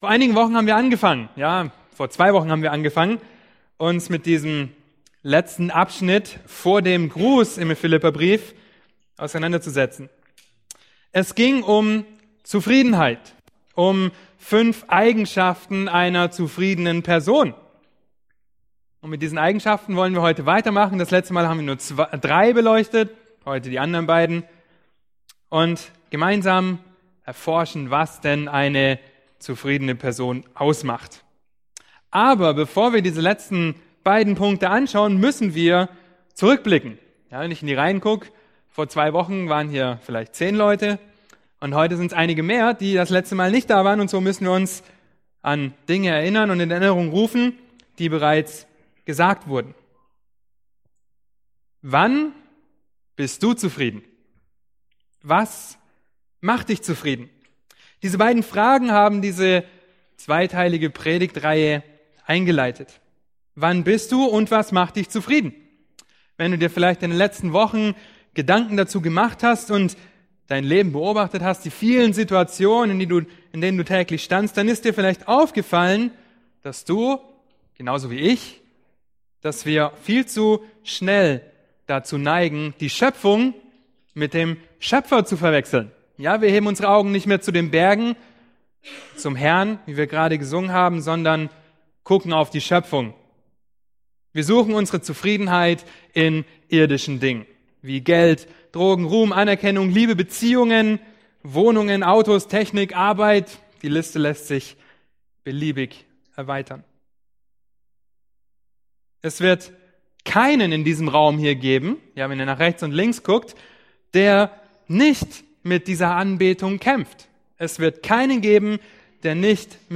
Predigt: "1.